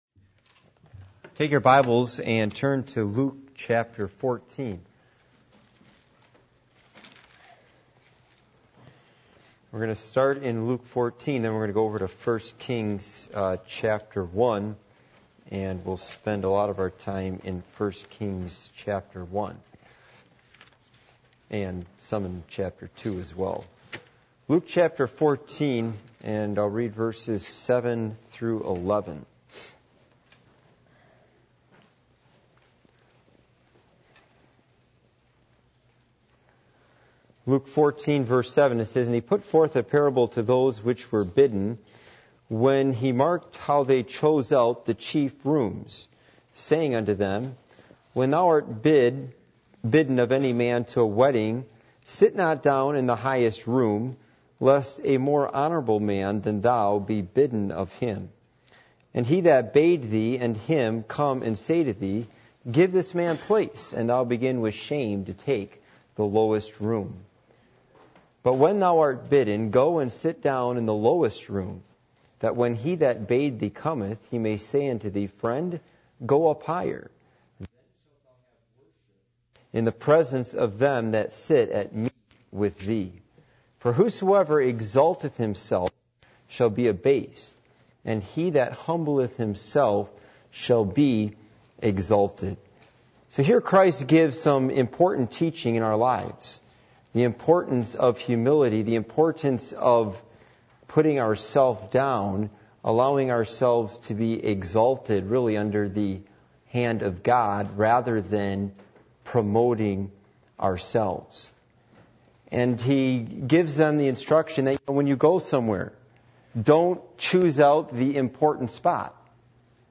Passage: 2 Samuel 3:24-27 Service Type: Midweek Meeting